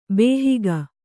♪ bēhiga